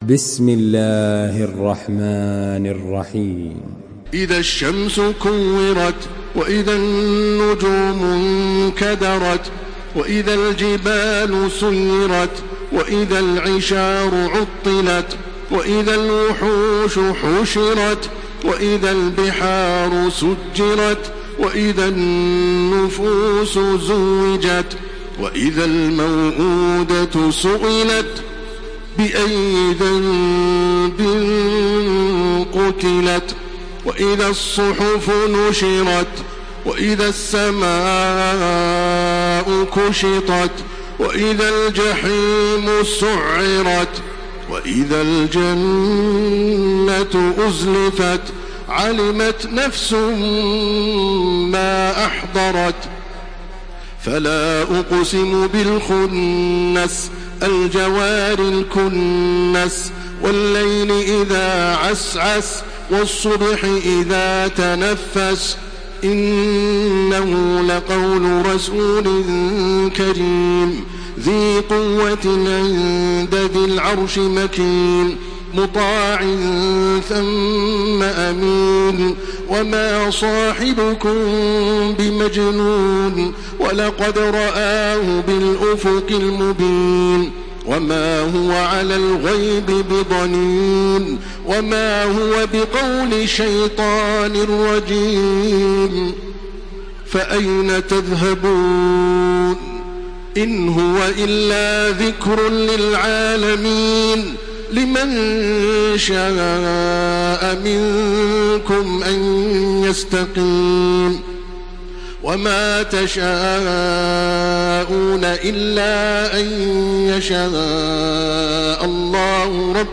Surah التكوير MP3 in the Voice of تراويح الحرم المكي 1429 in حفص Narration
Listen and download the full recitation in MP3 format via direct and fast links in multiple qualities to your mobile phone.